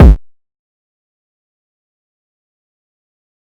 Lunch77 Kick 4.wav